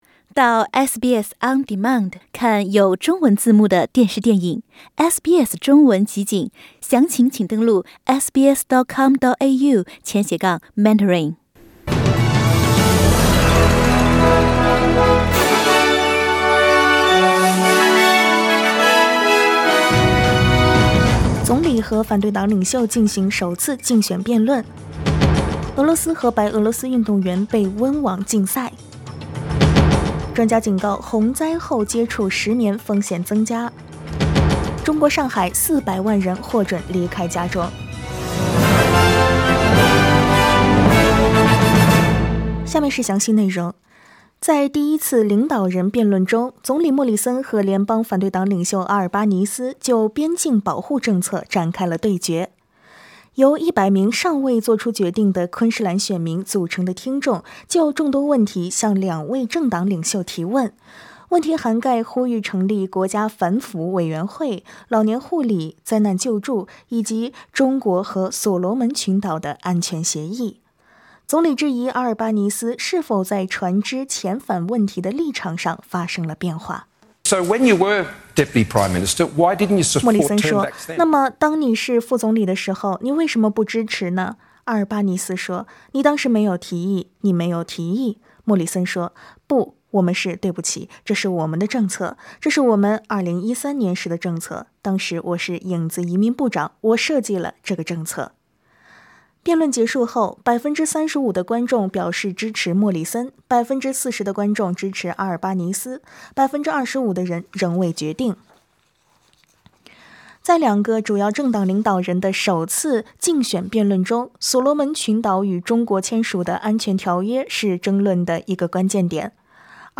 SBS早新闻（4月21日）